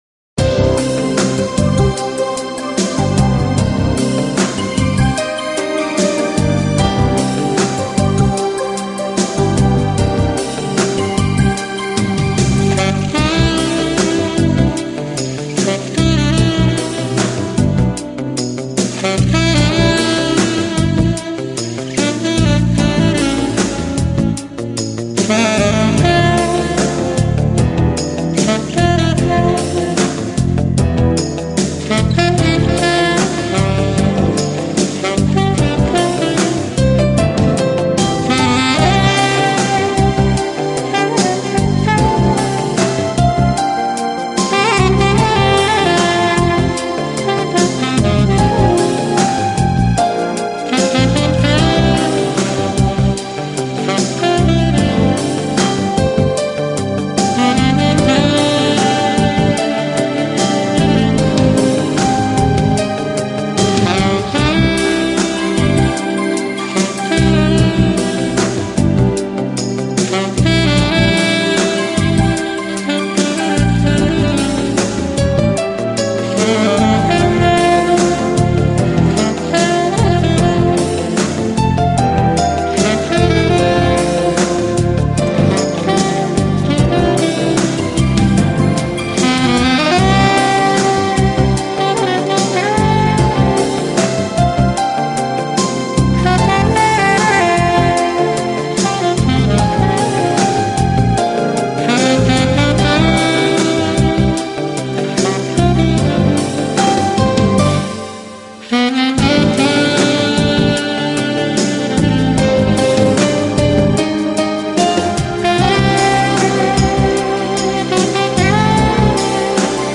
爵士萨克斯
Genero/Genre: Smoth Jazz/Orchestral Pop
用萨克管演奏情调爵士乐，上世纪六十年代开始很走红，到上个世纪七十年代达到顶峰，